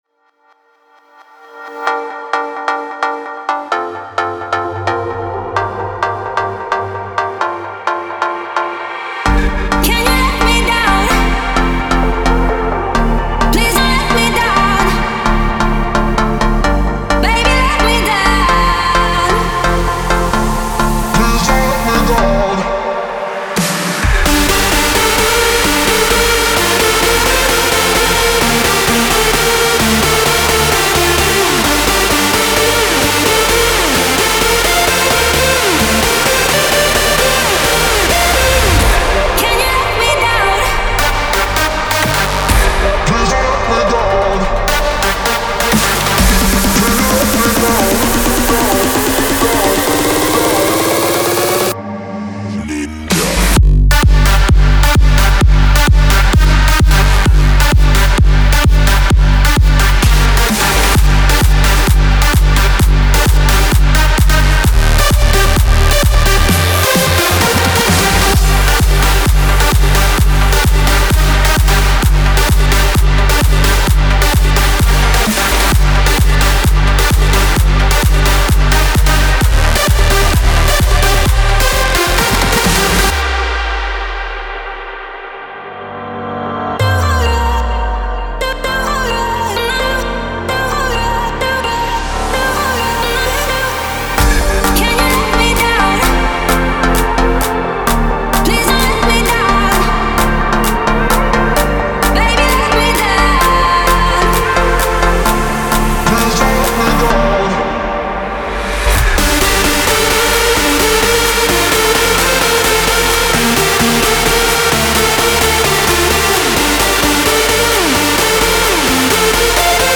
это энергичная электронная трек в жанре EDM